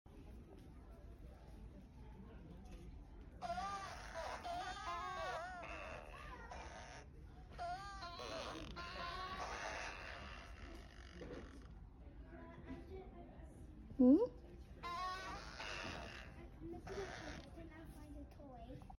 Saw these Cute and Fuggly toy at Kmart. They make fart sounds automatically everytime a person passes nearby 😆😅.